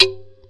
手鼓节拍器 " 手鼓廉价麦克风会议Uno(01)
描述：用廉价的塑料玩具麦克风录制的对着塑料手鼓打拍子的声音。
标签： 节拍 口技 口技 非洲鼓 共振 共振